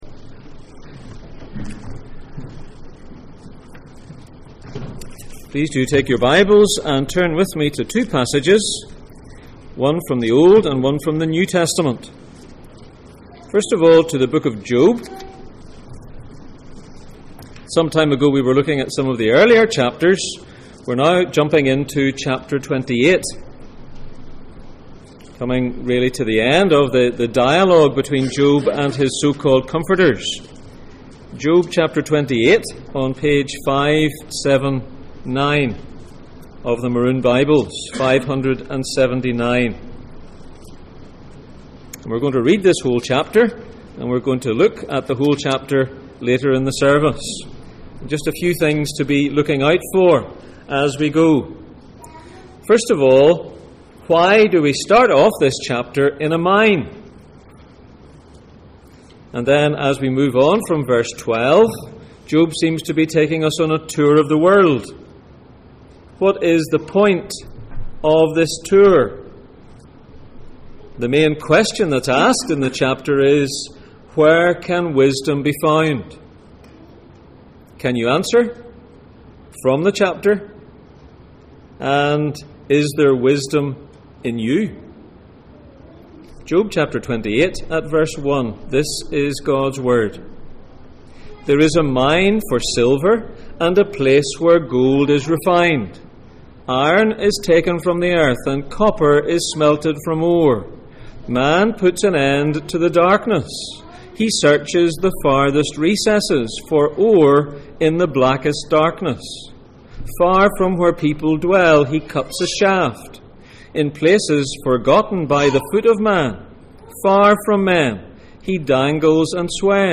Job 25:6 Service Type: Sunday Morning %todo_render% « Testing